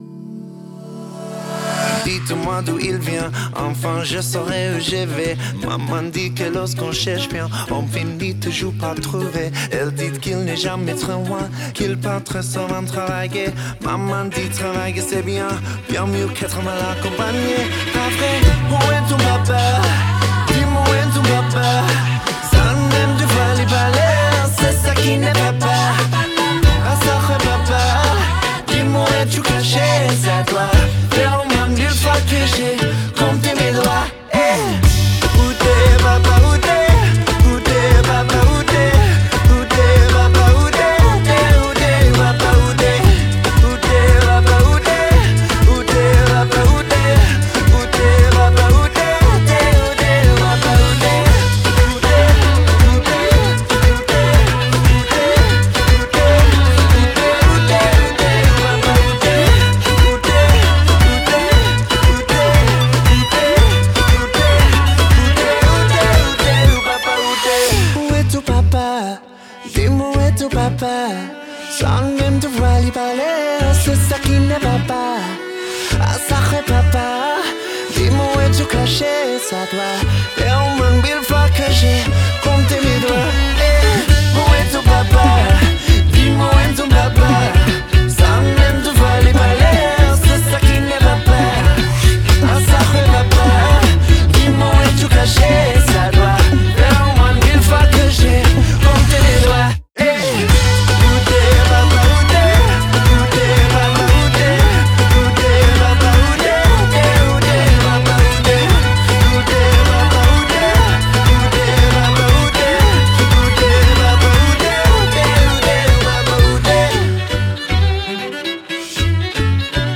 BPM117
Celloboxer